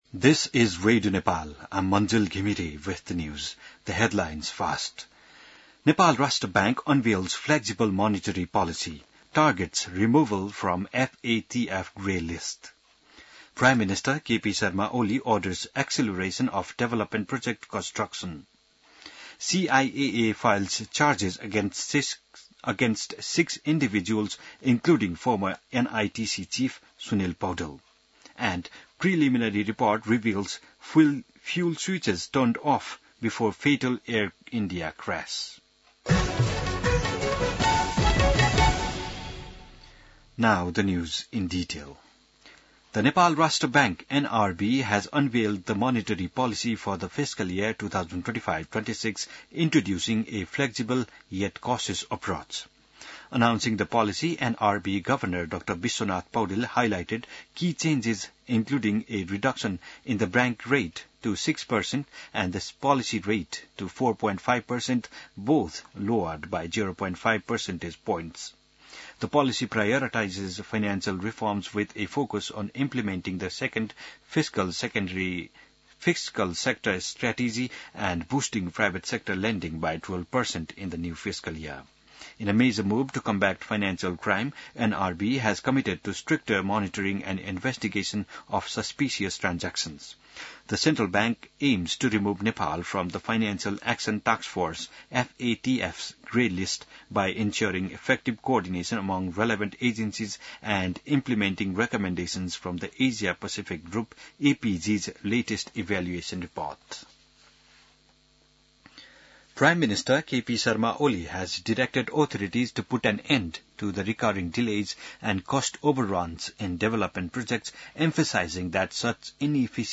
An online outlet of Nepal's national radio broadcaster
बिहान ८ बजेको अङ्ग्रेजी समाचार : २८ असार , २०८२